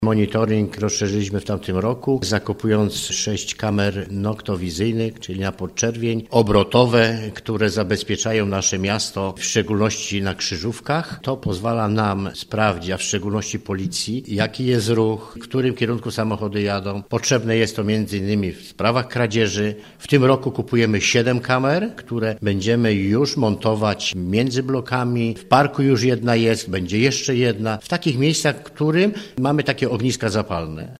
’- Od ubiegłego roku zwiększamy liczbę kamer, które wyłapują zachowania niezgodne z prawem – informuje Andrzej Kamyszek, burmistrz Jasienia.